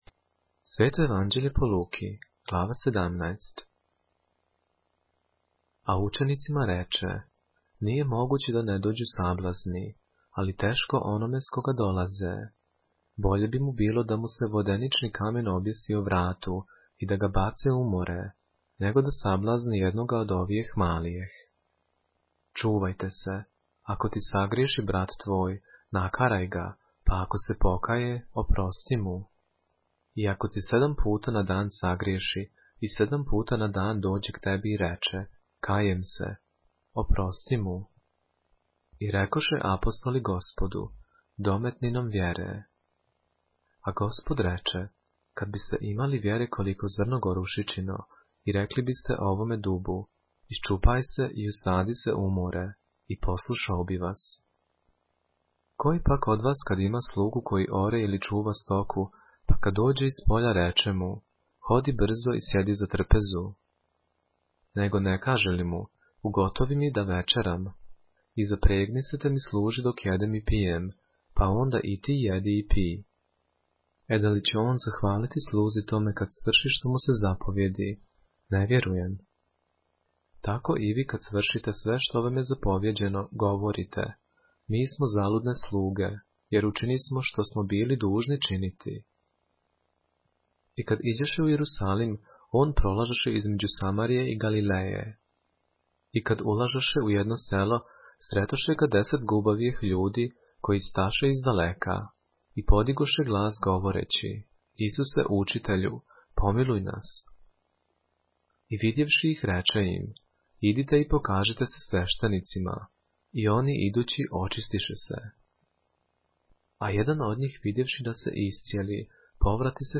поглавље српске Библије - са аудио нарације - Luke, chapter 17 of the Holy Bible in the Serbian language